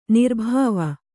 ♪ nirbhāva